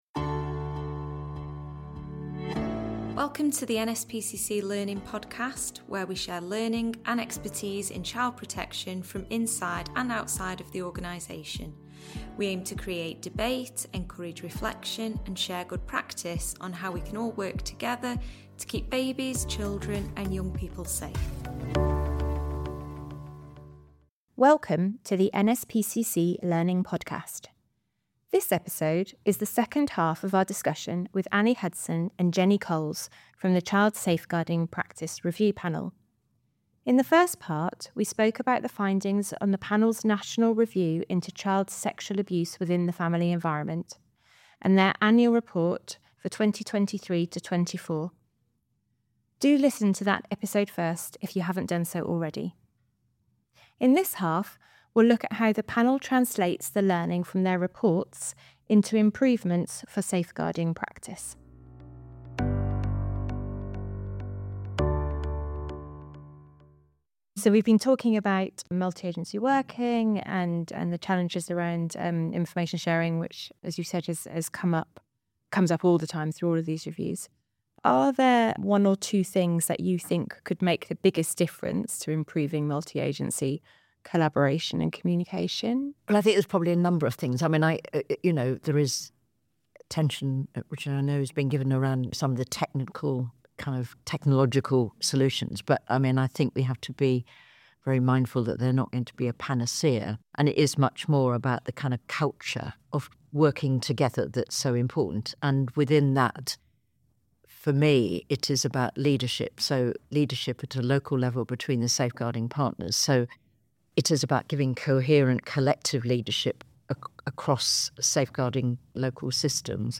Part two of a discussion with members of the Child Safeguarding Practice Review Panel.